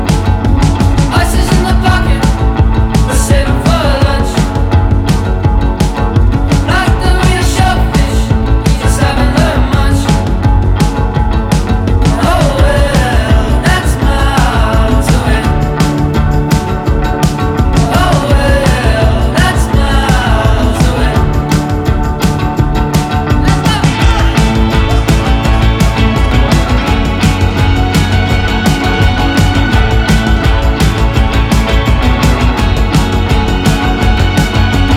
Жанр: Поп музыка / Альтернатива
Indie Pop, Alternative